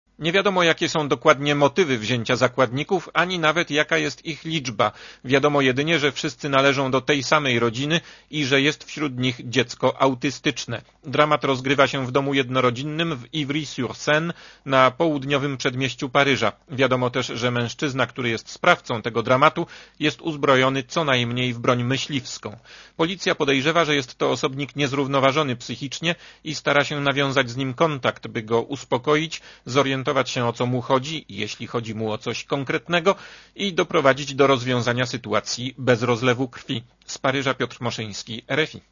Korespondencja z Paryża (152Kb)